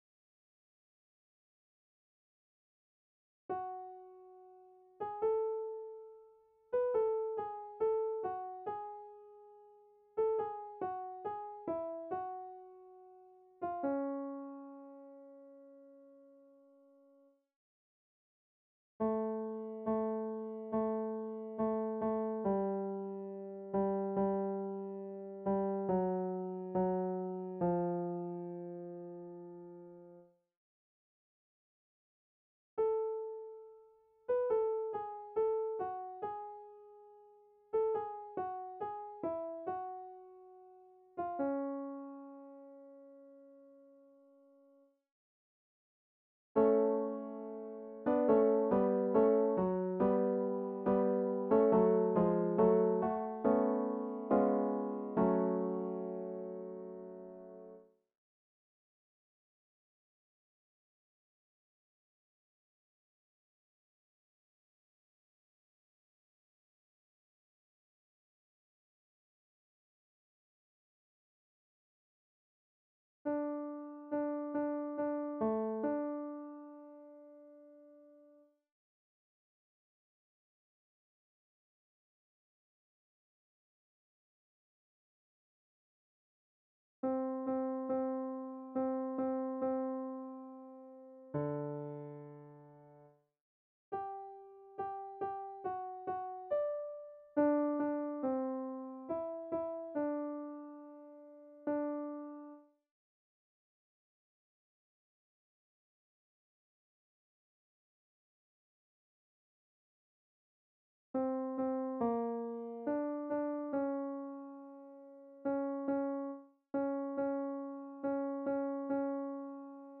4 voix  mp3
Choeur
La Pavane op. 50 en fa dièse mineur est une œuvre symphonique avec chœurs, écrite par Gabriel Fauré en 1887.
Pavane-Choeur.mp3